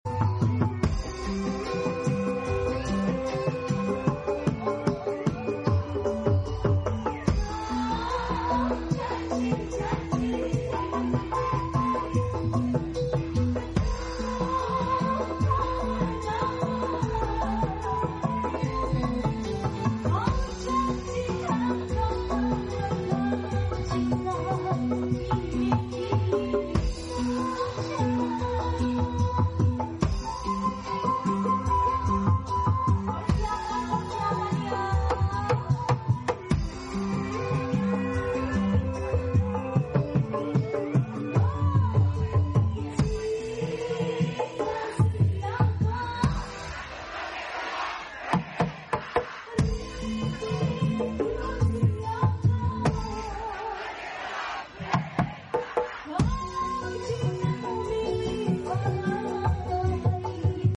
KENDANG